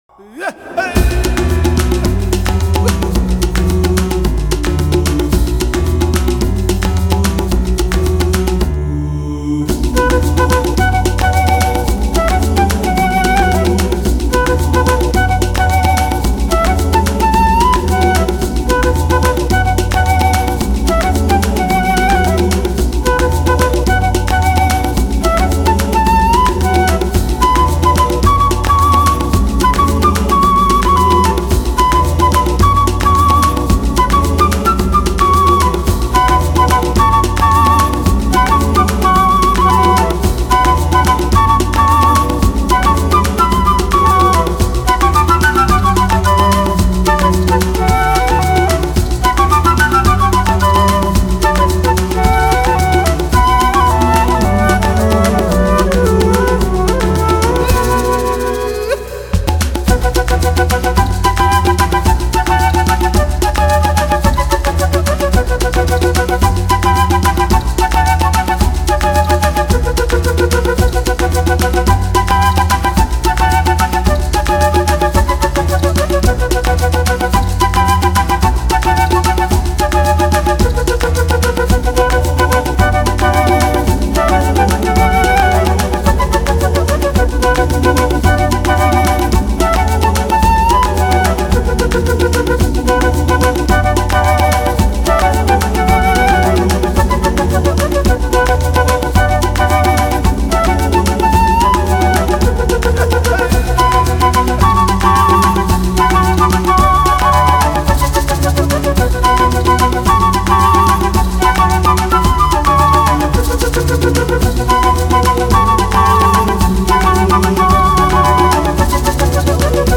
新世紀長笛
神秘人声的萦绕忽隐忽现